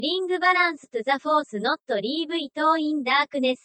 Tags: Star Wars japanese dub